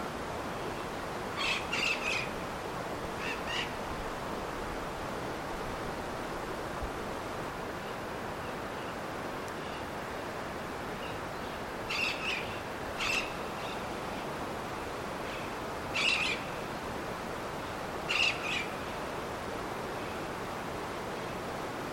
Choroy (Enicognathus leptorhynchus)
Nombre en inglés: Slender-billed Parakeet
Localidad o área protegida: Parque Nacional Conquillio
Condición: Silvestre
Certeza: Fotografiada, Vocalización Grabada
choroy.mp3